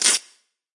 来自我的卧室的声音" 磁带延伸( 冻结)
描述：在Ableton中录制并略微修改的声音